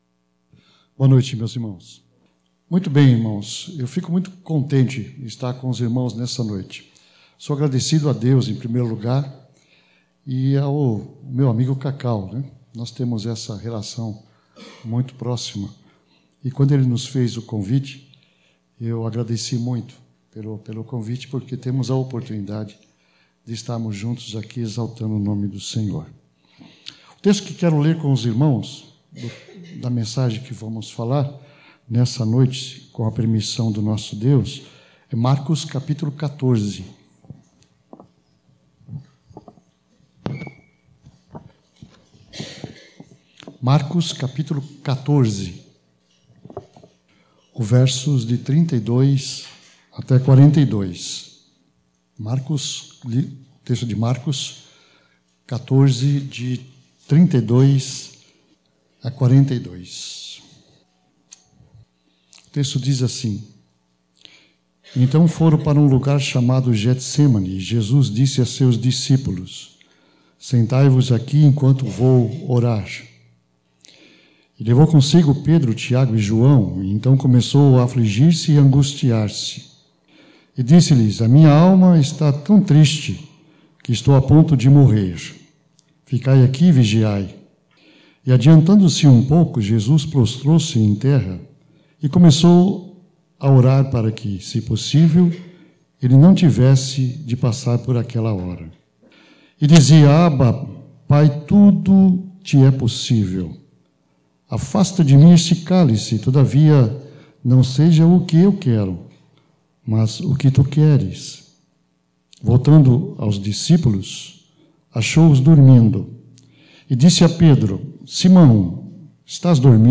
Mensagem: A Agonia de Jesus no Jardim